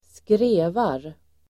Uttal: [²skr'e:var]